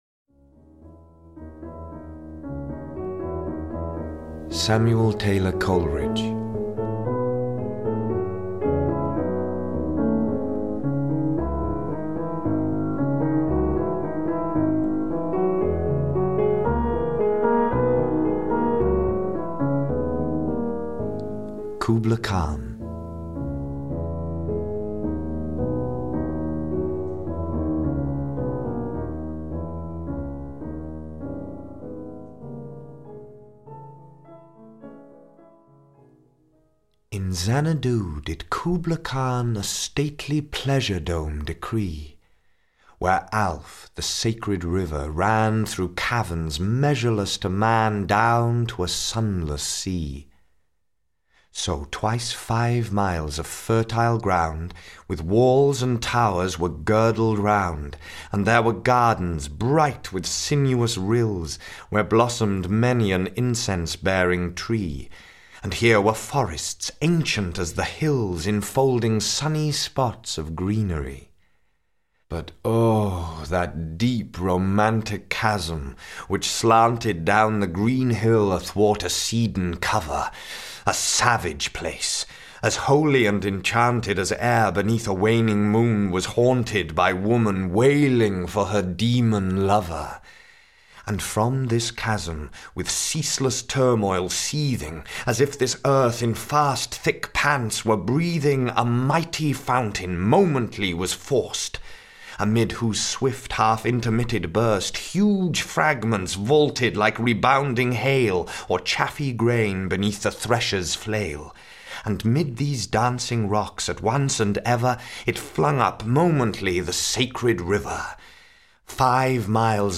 Audio knihaThe Great Poets – Samuel Taylor Coleridge (EN)